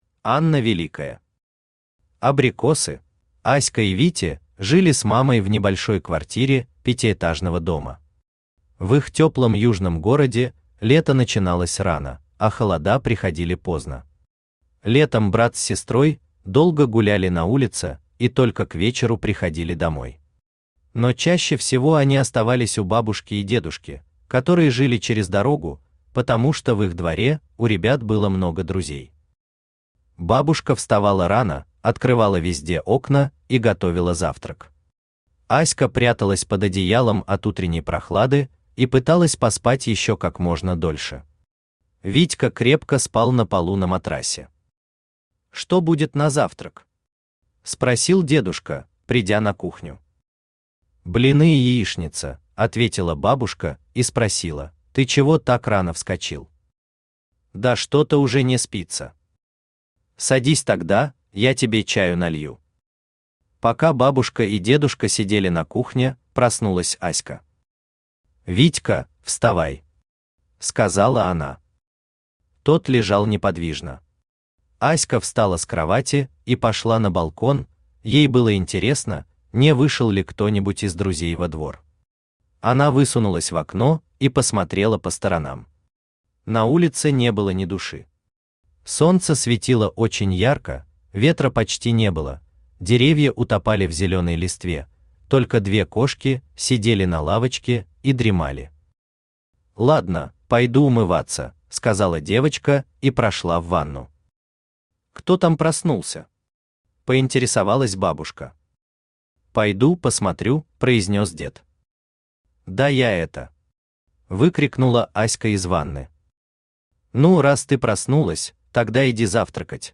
Аудиокнига Абрикосы | Библиотека аудиокниг
Aудиокнига Абрикосы Автор Анна Великая Читает аудиокнигу Авточтец ЛитРес.